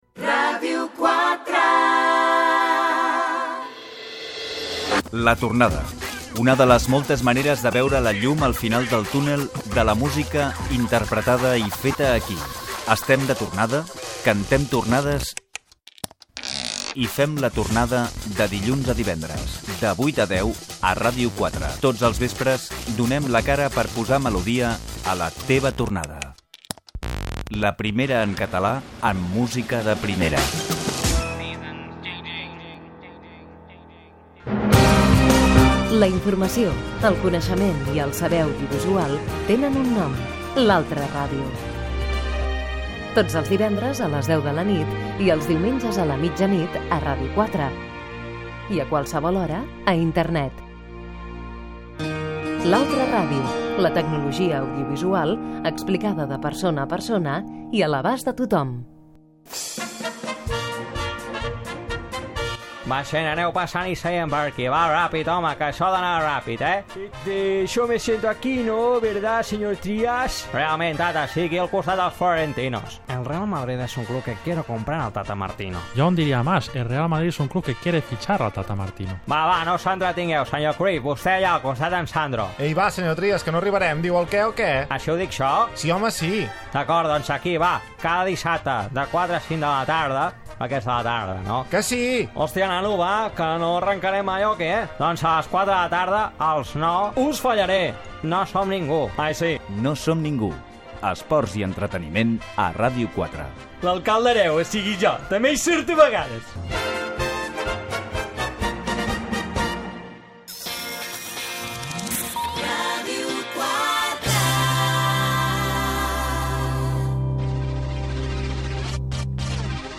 Indicatiu de la ràdio, promoció dels programes "La tornada" , "L'altra ràdio" i "No som ningú", Indicatiu, sintonia del programa, sumari de continguts, agenda de concerts
FM